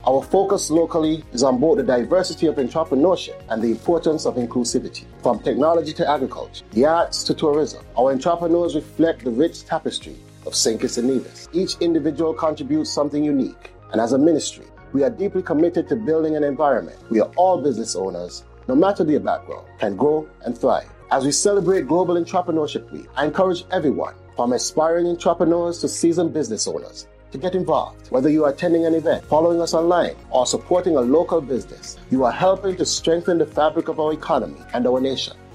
Minister of Entrepreneurship and Creative Economy, the Hon. Samal Duggins gave a message in light of the week, here is a snippet: